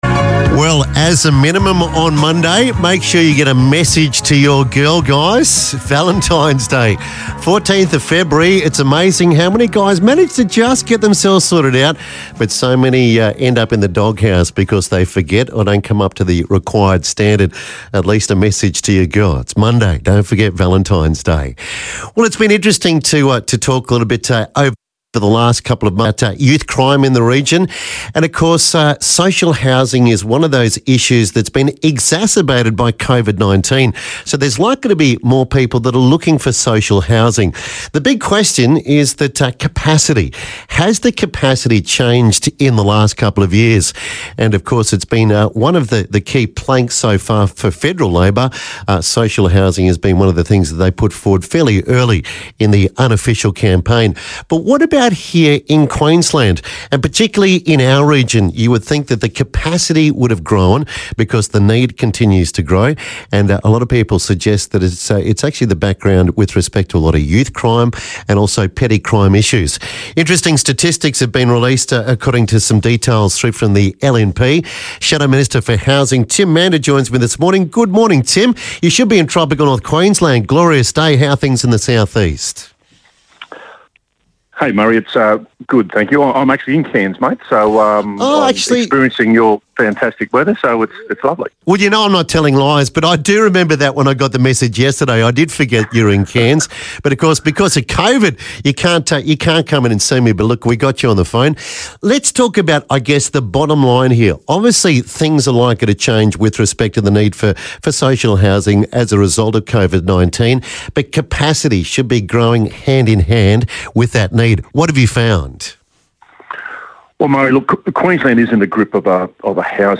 chats with Queensland's Shadow Minister for Housing and Public Works Tim Mander about social housing in Cairns